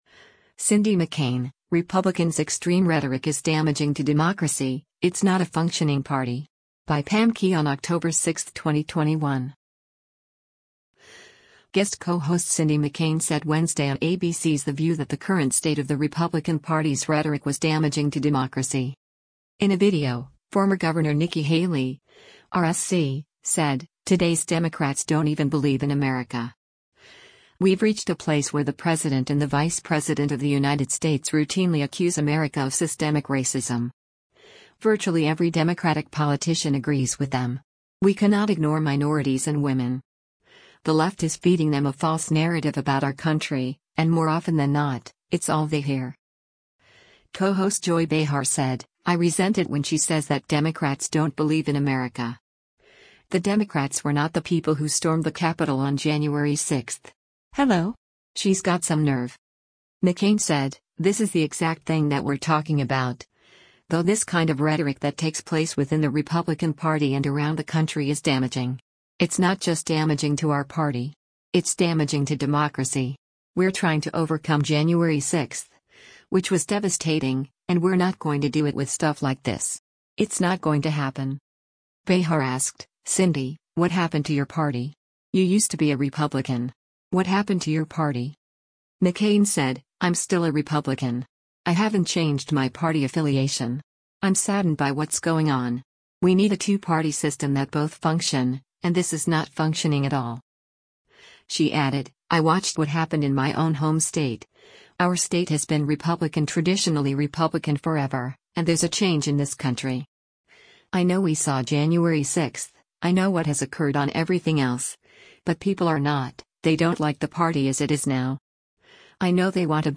Guest co-host Cindy McCain said Wednesday on ABC’s “The View” that the current state of the Republican Party’s rhetoric was “damaging to democracy.”